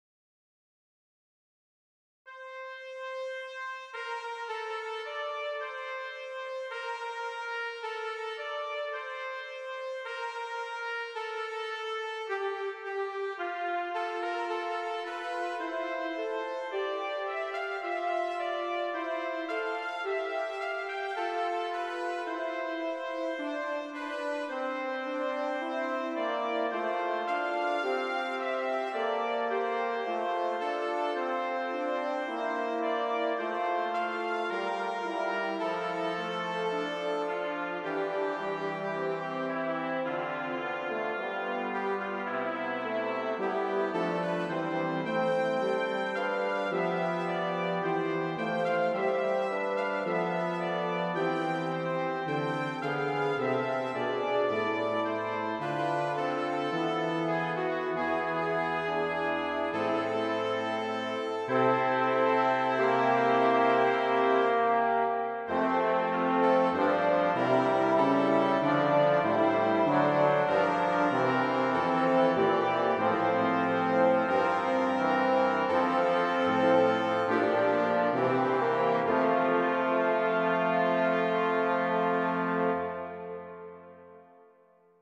mp3 set for brass quintet